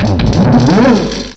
cry_not_eelektross.aif